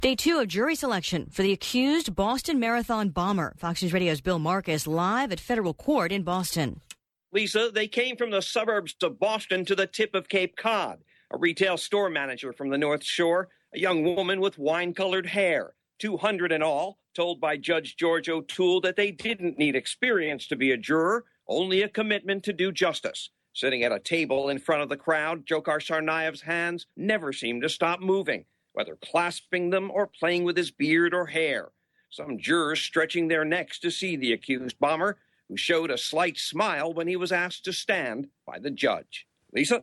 (BOSTON) JAN 6 – LIVE 2PM –
2pm-live-day-2-tsarnaev-jury-selection.mp3